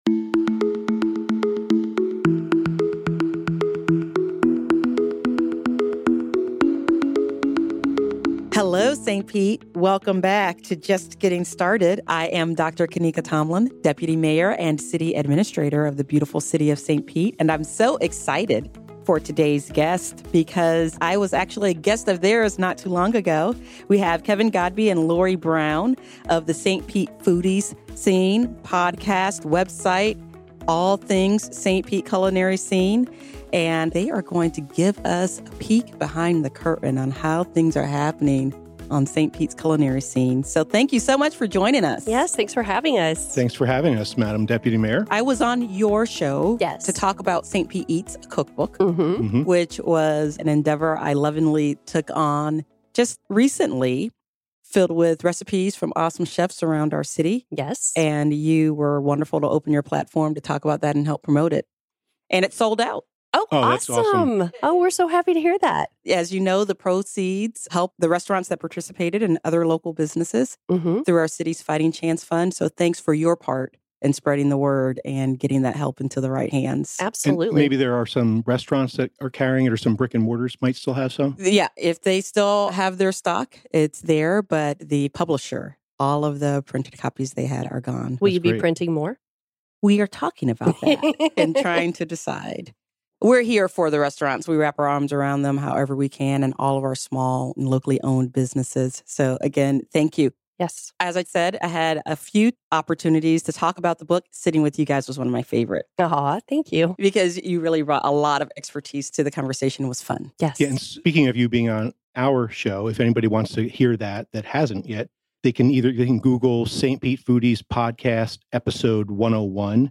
They also discuss the dramatic effects of the pandemic, and how restaurants - both locally and around the country - are adapting. Disclaimer: A segment of this interview is devoted to the restaurant capacity limits imposed by state government in the wake of Covid-19.